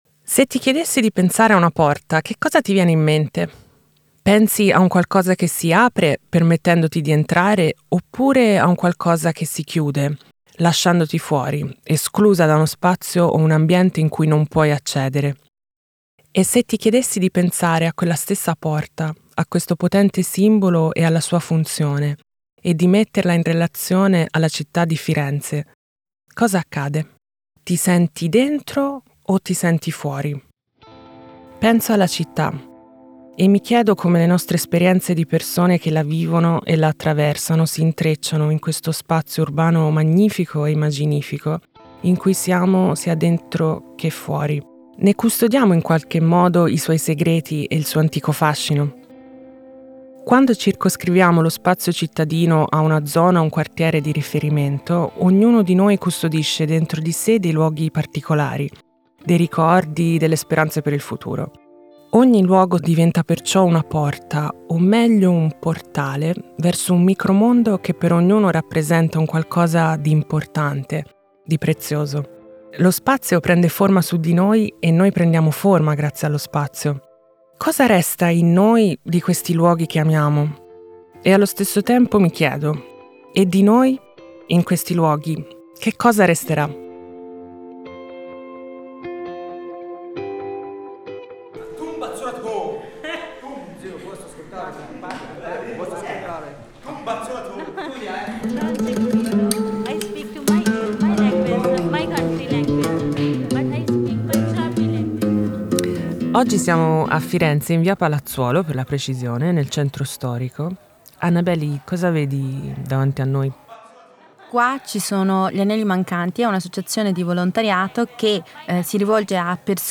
Genre Documentary
PortaMi. Racconto collettivo urbano dalla città di Firenze è una produzione audio nata all’interno del laboratorio di narrazione sonora Popcast promosso dal Centro Interculturale Gli Anelli Mancanti in collaborazione con Radio Papesse e Ah Mam Studio. PortaMi è un racconto collettivo in cui prospettive personali, paesaggi sonori, interviste e scrittura creativa si intrecciano in un lavoro a più voci, disegnando un attraversamento della città che mette al centro i soggetti e le loro storie. Dieci persone di età, percorsi e formazioni diverse fra loro hanno esplorato il tessuto sonoro della città e hanno dato forma a un racconto multifocale che attraversa Firenze al di fuori dei tracciati più comuni in un percorso tra possibili soglie che si attiva nel momento in cui ci si ferma a pensare: quali sono le “porte” della città?